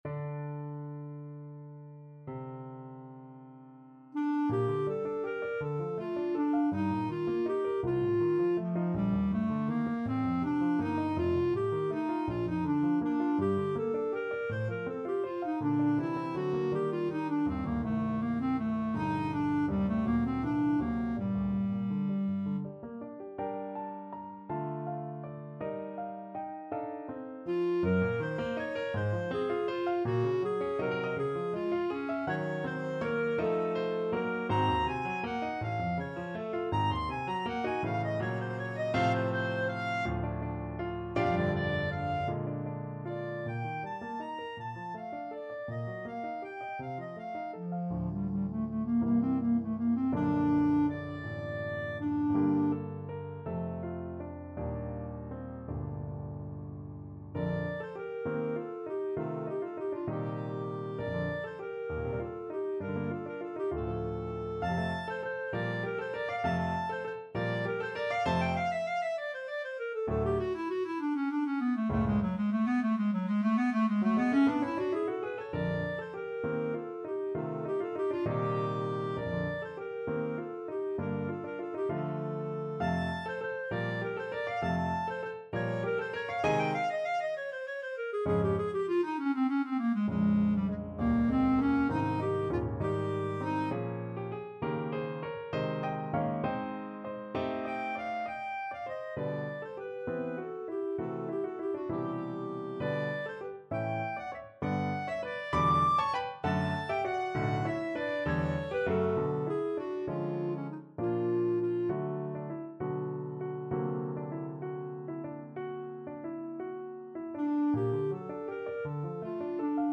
6/8 (View more 6/8 Music)
Classical (View more Classical Clarinet Music)